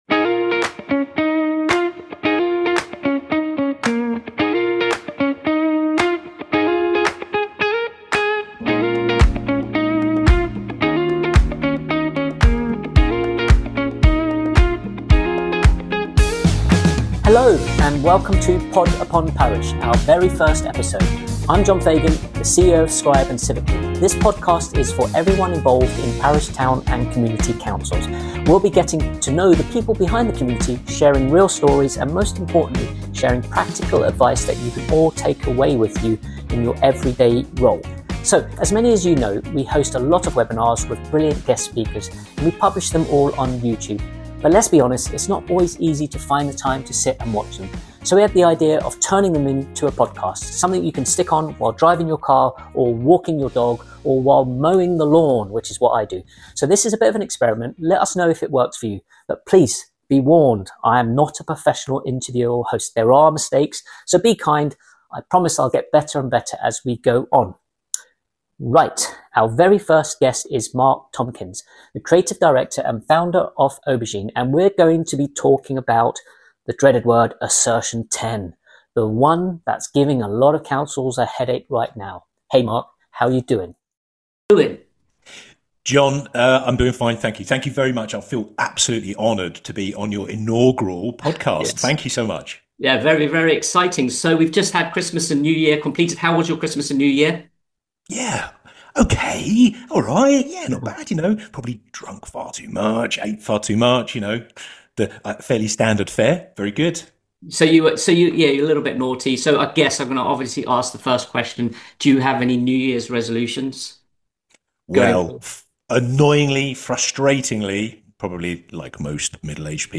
Full webinar recording
Live Audience Transition